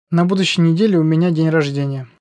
Dodatkowo program kształci rozumienie ze słuchu (10 000 nagrań rodowitych Rosjan), utrwala zasady gramatyki i ortografii, a opcja nagrywania przez mikrofon pozwala nabrać właściwego akcentu.